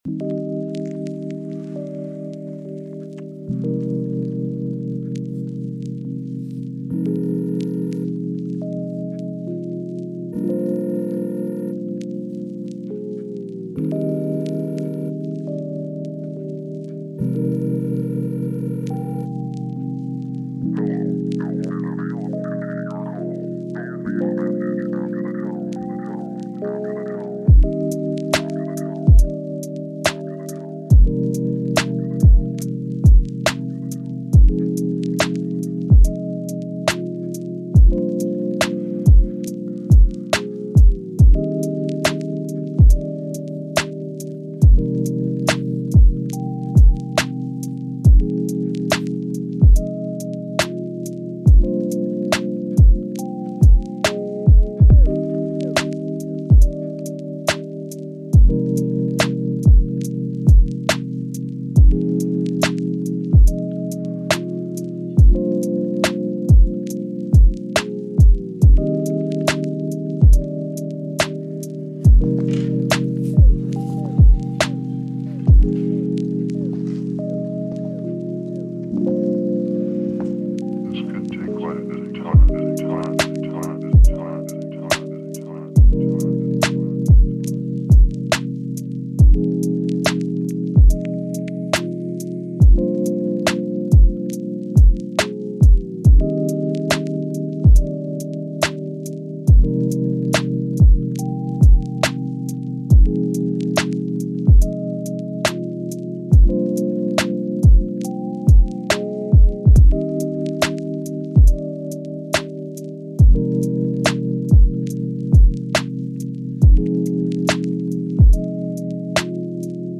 Résonance 36 Hz : Alignement